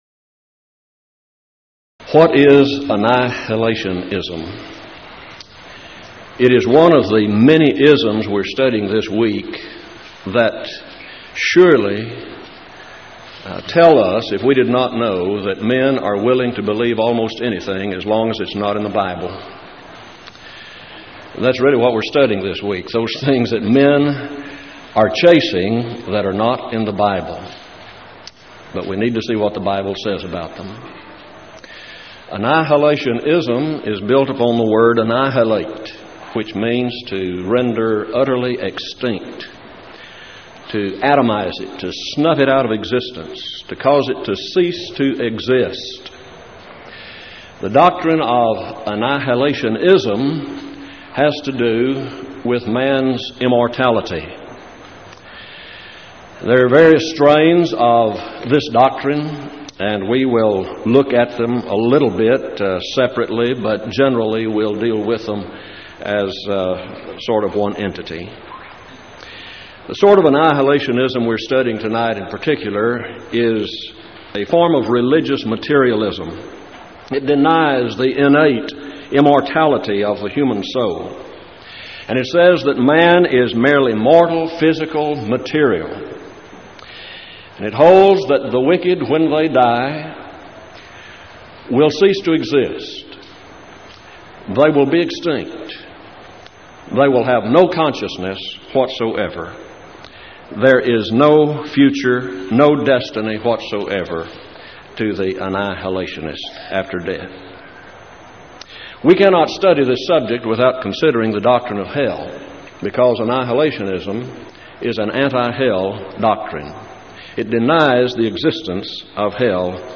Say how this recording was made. Series: Power Lectures Event: 1997 Power Lectures